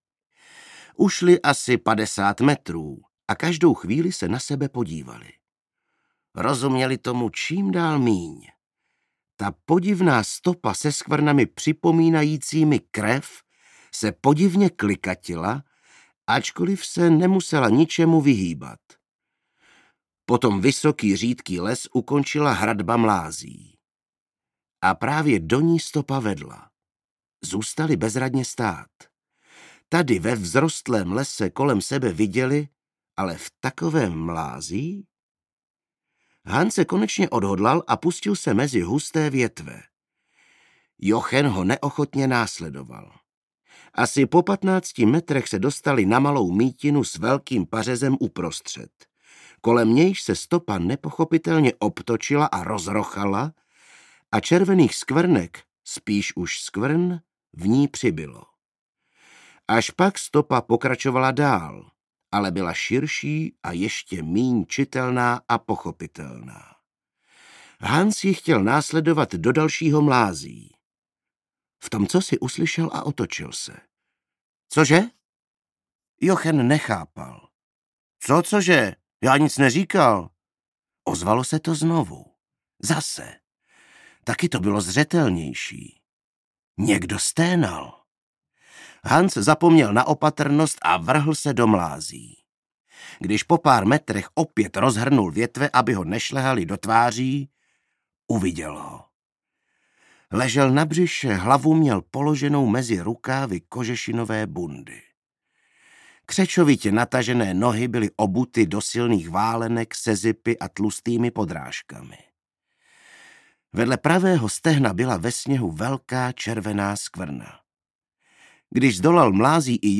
Audiobook
Read: Miroslav Černý